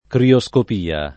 crioscopia [ krio S kop & a ] s. f. (fis.)